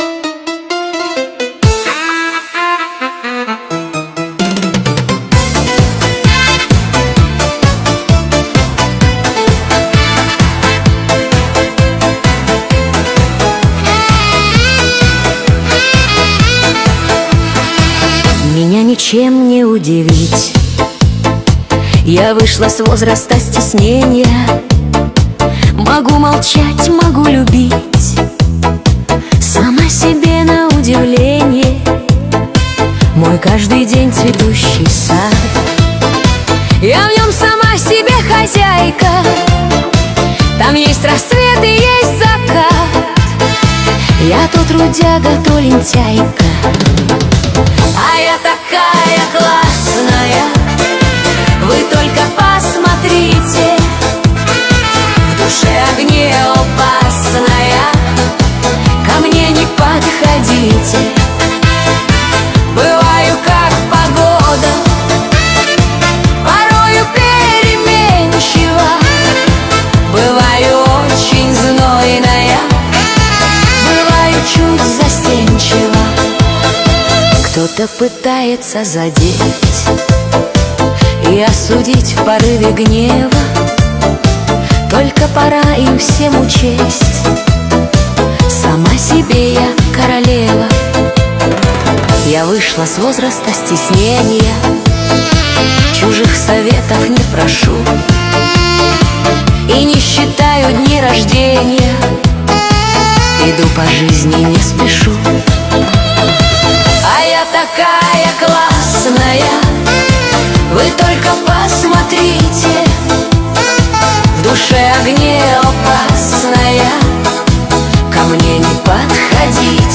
13 декабрь 2025 Русская AI музыка 94 прослушиваний